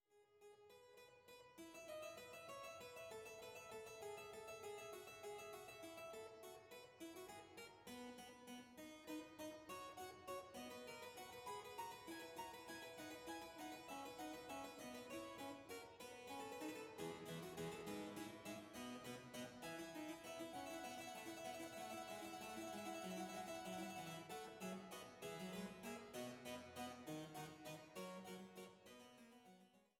Cembalo